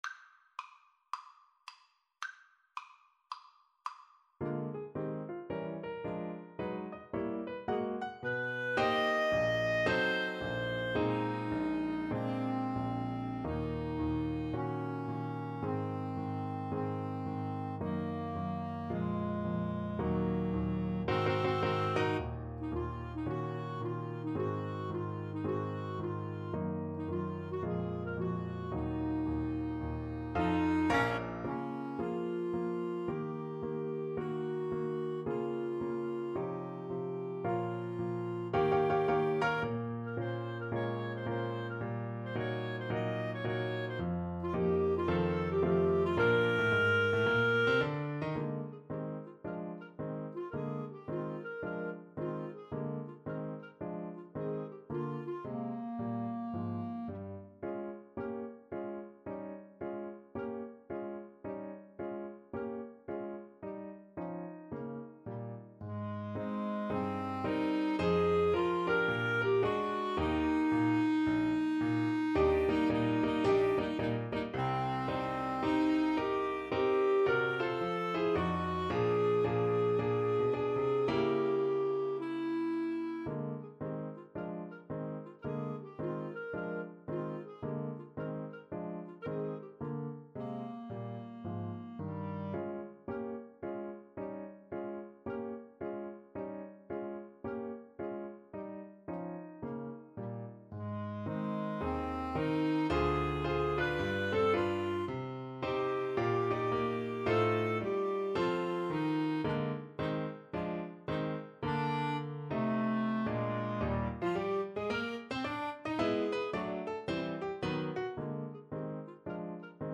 Moderato =110 swung
Classical (View more Classical Clarinet Duet Music)